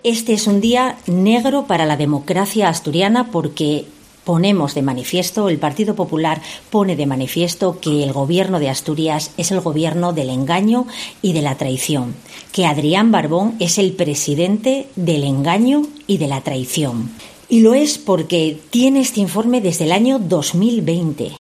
"Por eso nunca ha explicado en qué consiste la oficialidad amable, porque no existe", ha añadido en una rueda de prensa en la que ha reprochado al Ejecutivo haberse "reído de todos los asturianos".